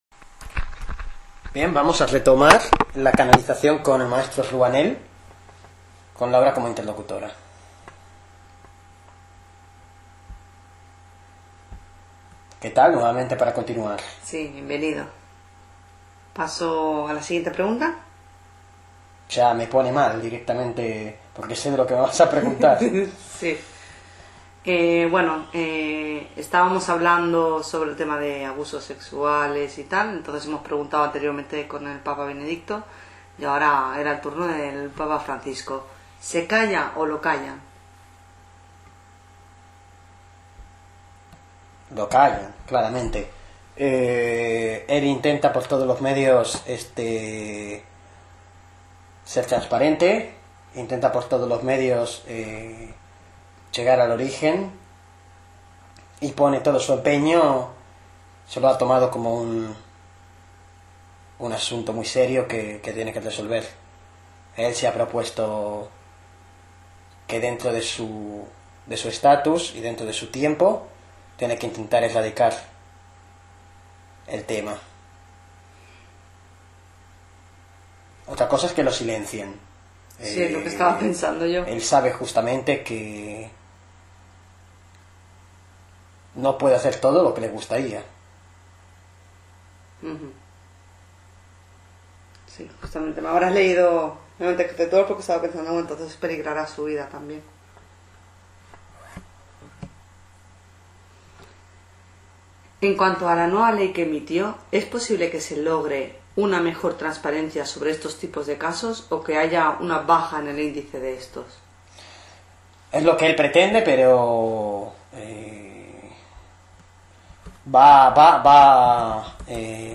Se realizaron dos sesiones telepáticas a día 18 de junio de 2019 sobre los controvertidos casos de pedofilia y pederastia dentro de las filas de la religión católica.